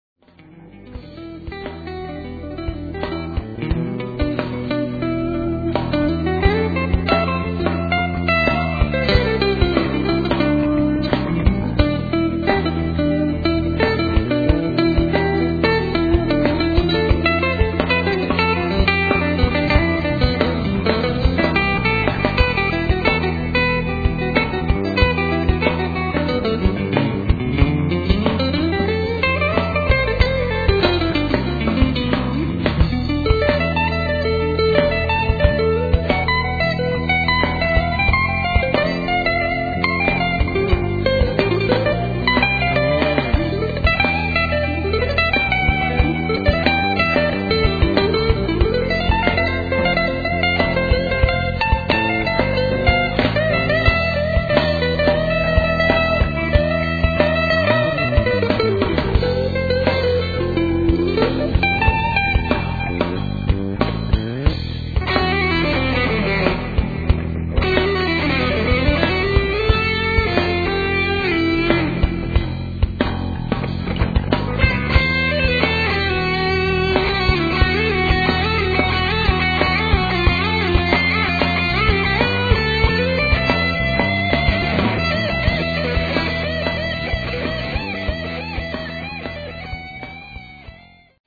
recorded at Midtown Recording